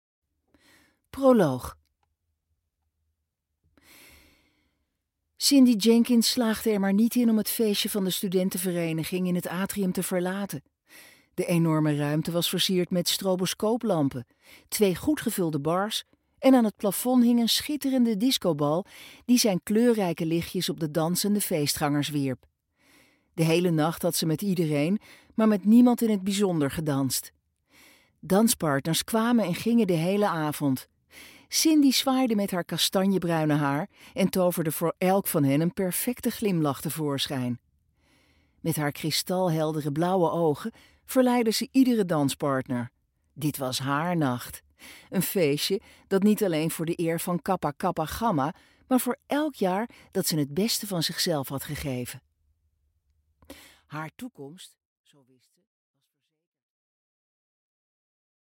Аудиокнига Moord met een hoger doel | Библиотека аудиокниг